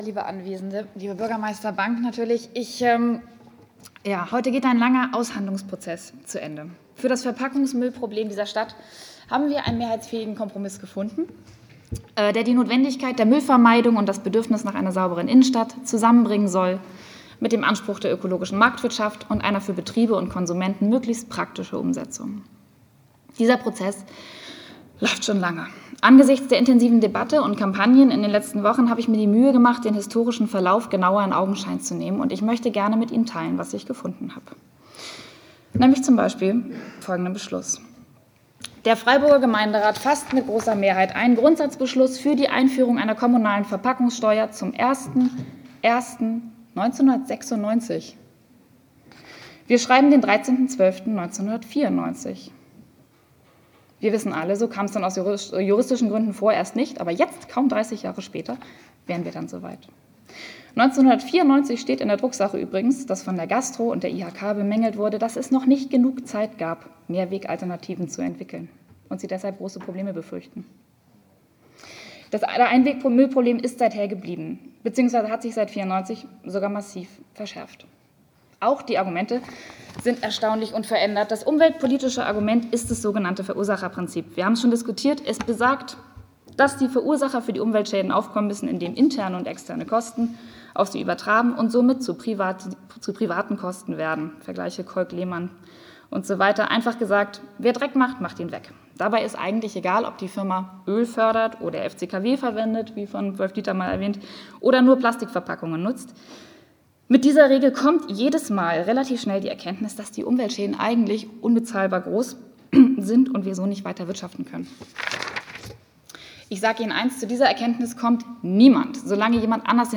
Bericht zu einigen Facetten der 5.Gemeinderatssitzung vom 6.Mai 2025